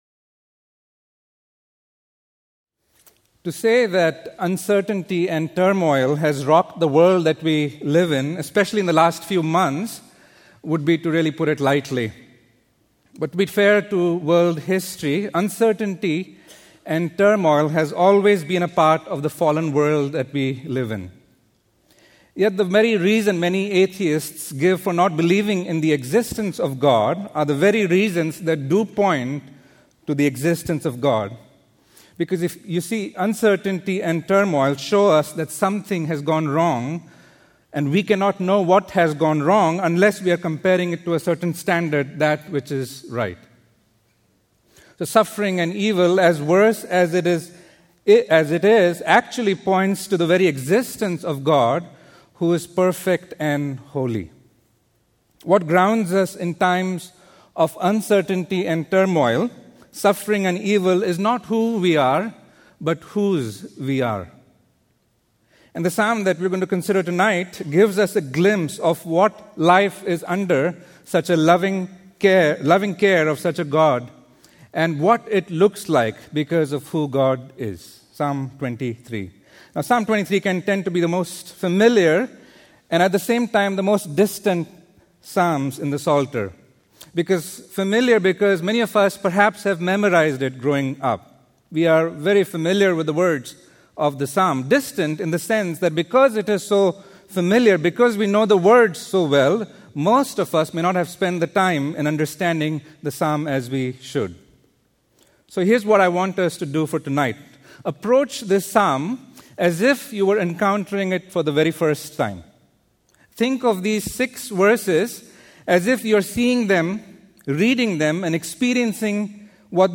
Sermons